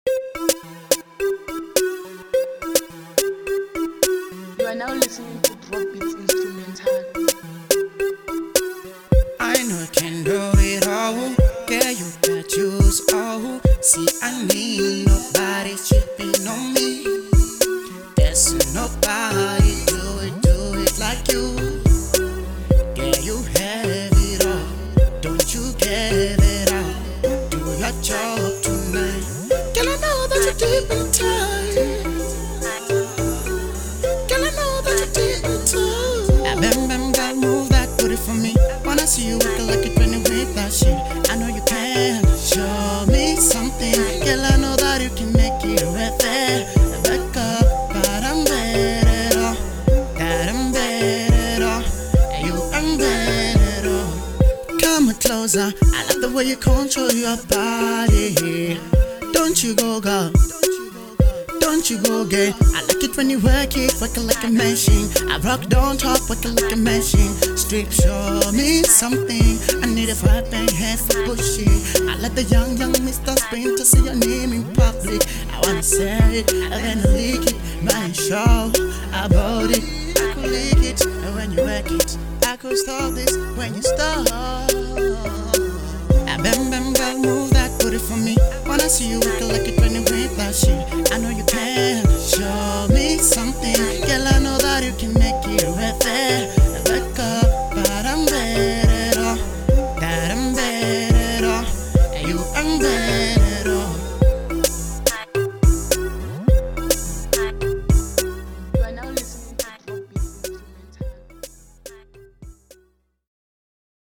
Single
Rnb